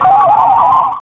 teleport.wav